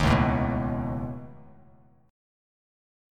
C#7sus2#5 chord